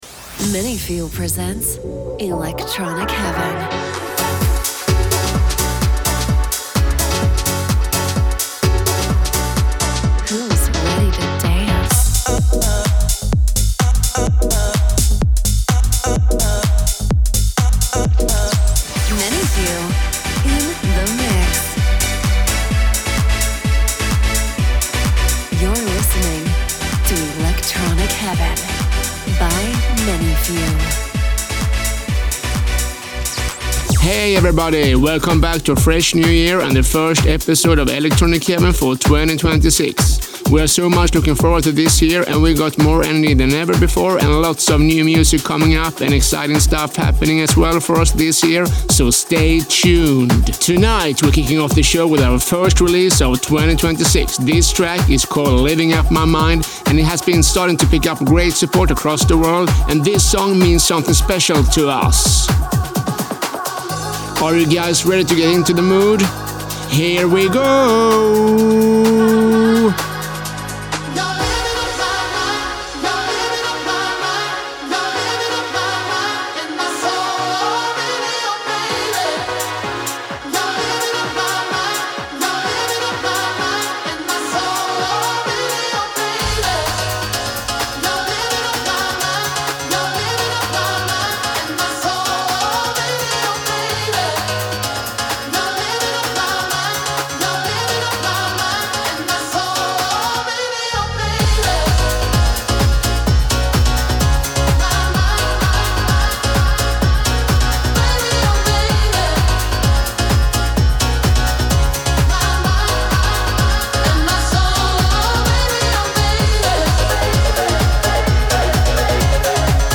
includes exclusive remixes, edits and unreleased tracks